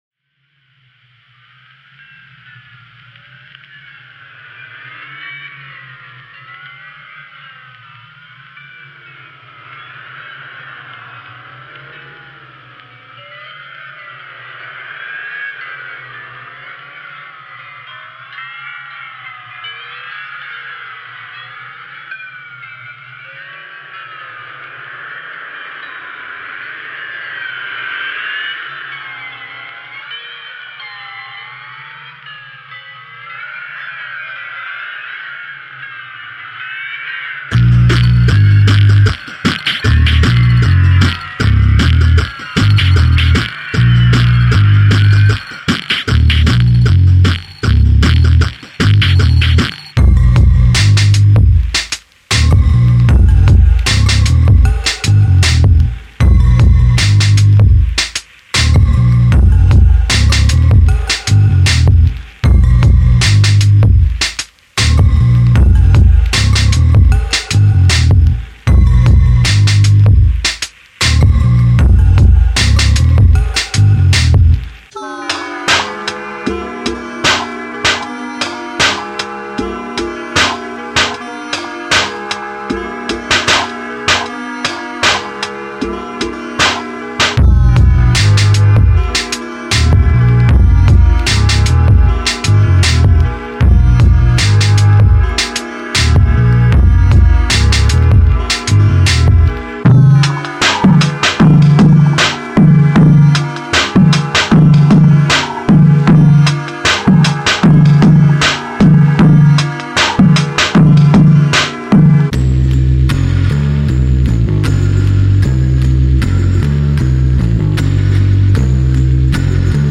Hip Hop
·     20 Bass Loops (Includes Synth, Electric & Sub Bass)